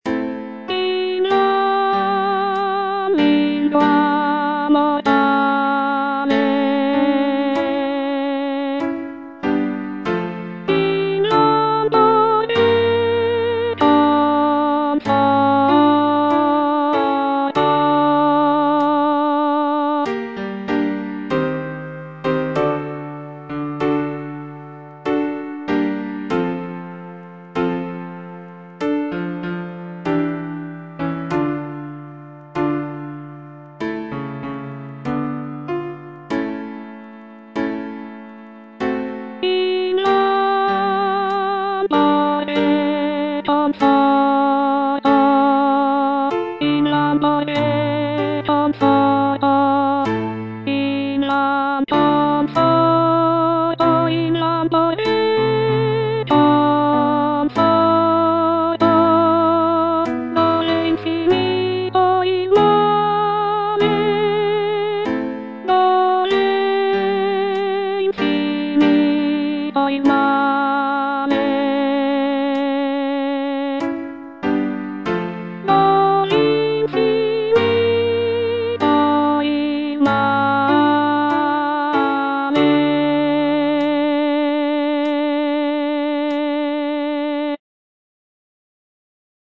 soprano II) (Voice with metronome) Ads stop